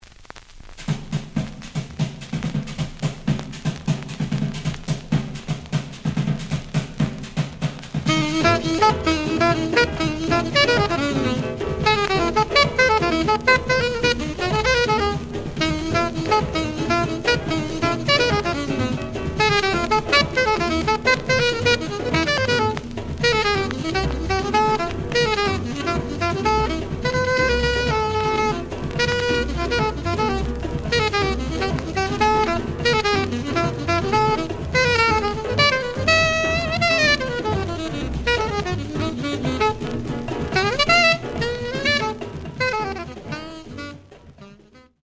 The audio sample is recorded from the actual item.
●Genre: Bop
Edge warp.